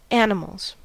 Ääntäminen
Ääntäminen US : IPA : [ˈæn.ə.məlz] Haettu sana löytyi näillä lähdekielillä: englanti Käännöksiä ei löytynyt valitulle kohdekielelle.